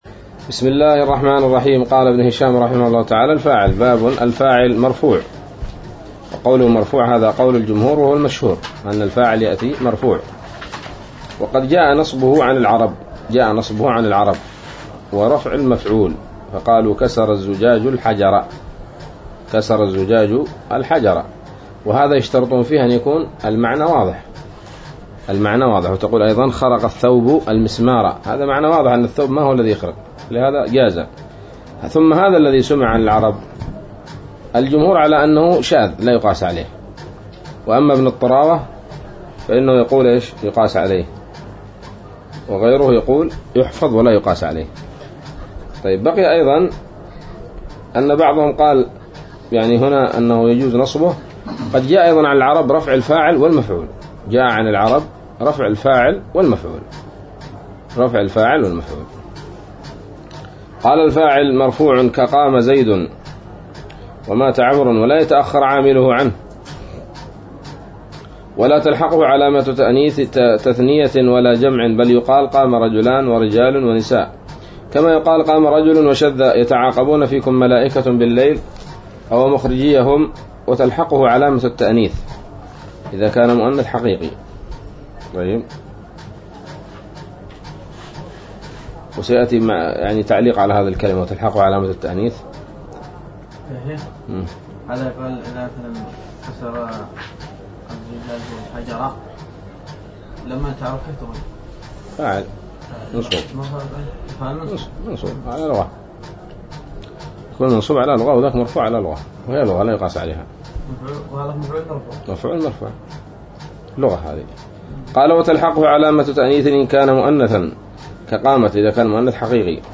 الدرس الثاني والسبعون من شرح قطر الندى وبل الصدى